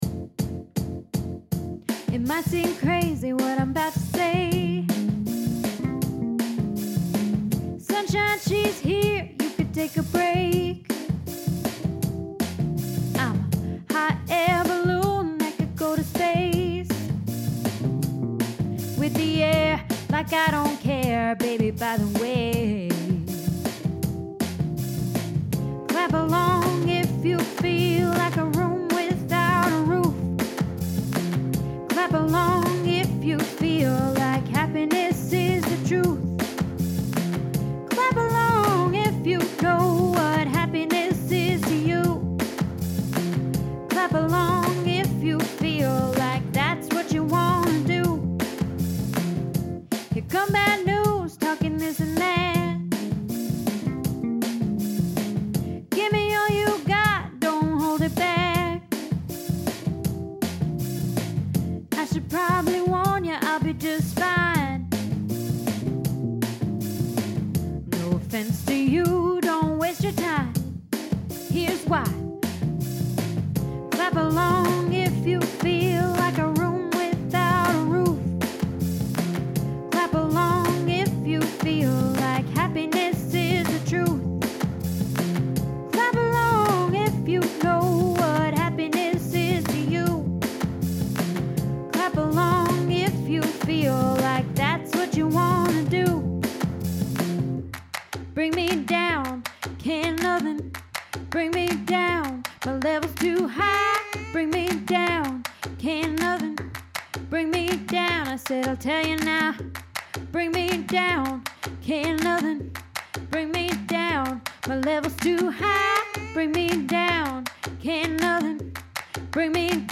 Happy Alto Lead